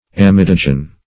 Amidogen \A*mid"o*gen\, n. [Amide + -gen.] (Chem.)